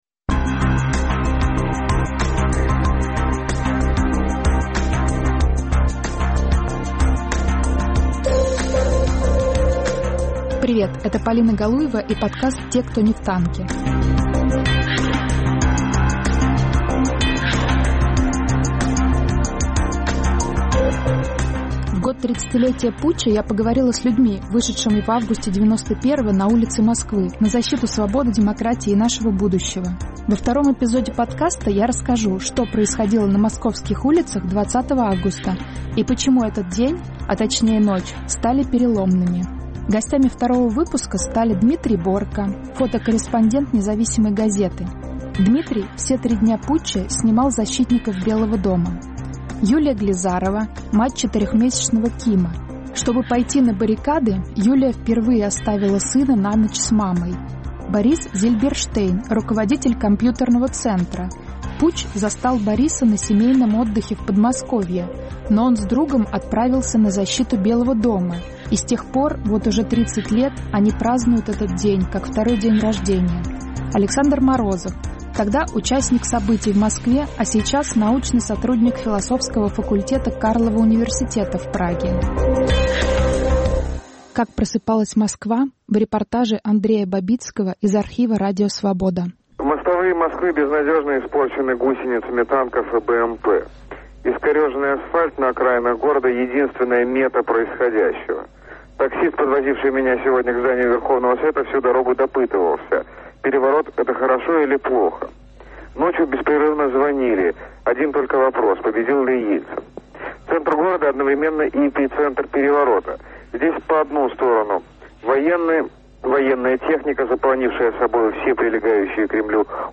Тысячи и тысячи пришли к Дому правительства, чтобы встать на его защиту "живым щитом". Очевидцы и участники событий рассказывают о том, что происходило в столице СССР в решающий день путча. Повтор эфира от 20 августа 2021 года.